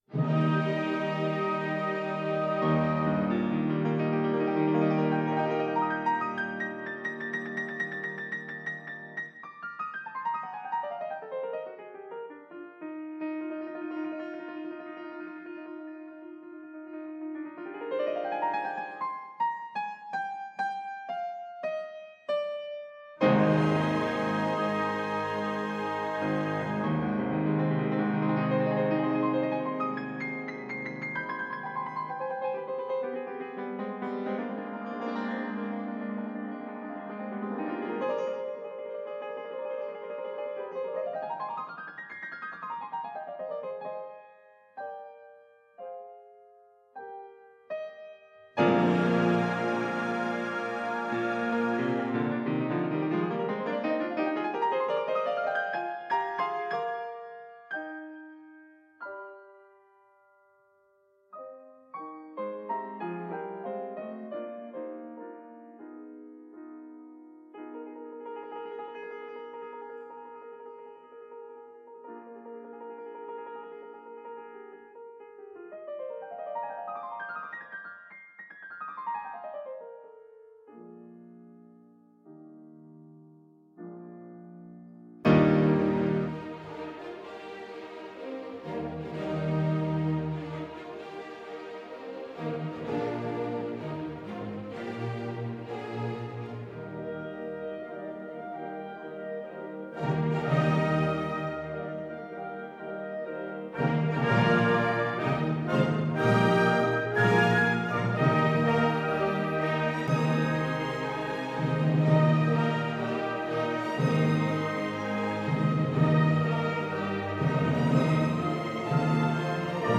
Piano
Instrument: Orchestra
Style: Classical